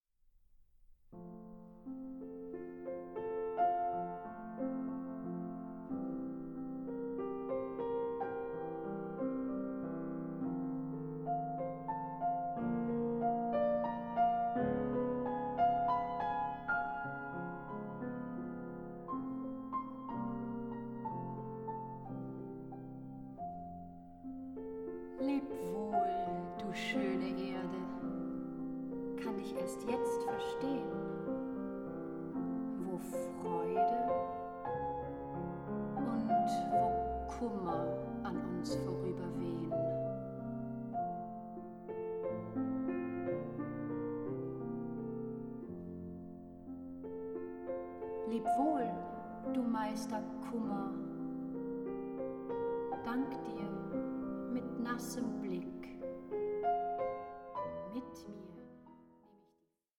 A VOCAL EXPLORATION OF HOMESICKNESS